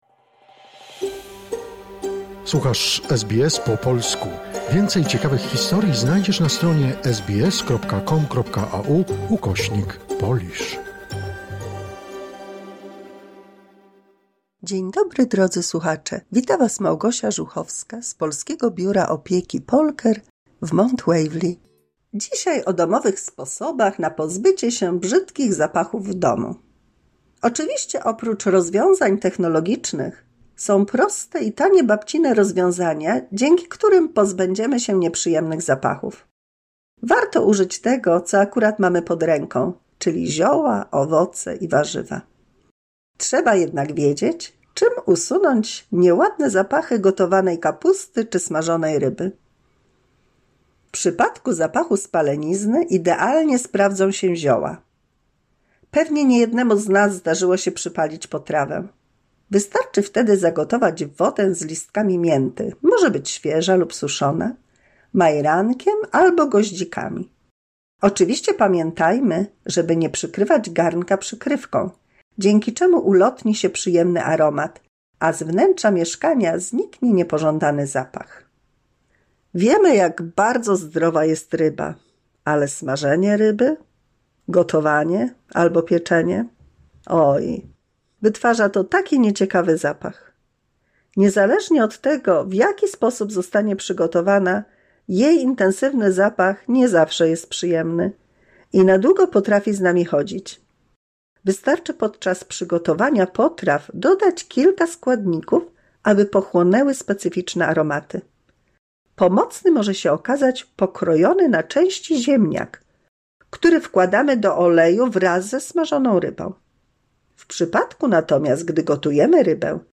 W 209 mini słuchowisku dla polskich seniorów dowiemy się o domowych sposobach na pozbycie się brzydkich zapachów.